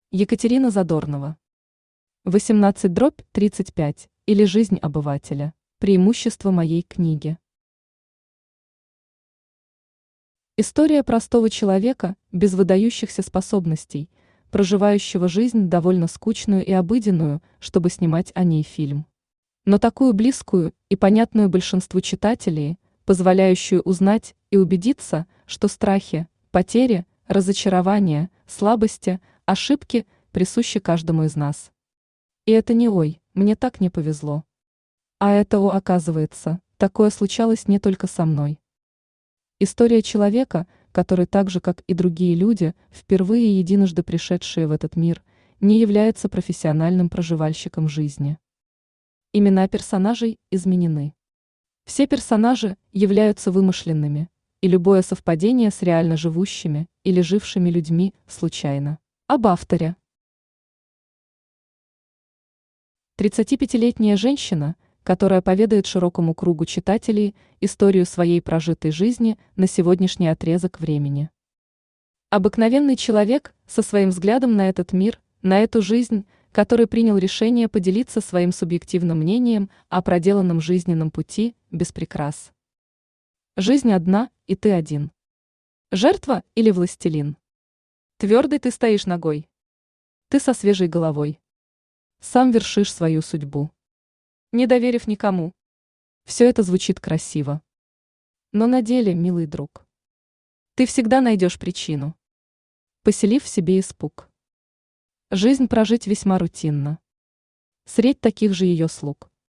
Аудиокнига 18/35, или Жизнь обывателя | Библиотека аудиокниг
Aудиокнига 18/35, или Жизнь обывателя Автор Екатерина Александровна Задорнова Читает аудиокнигу Авточтец ЛитРес.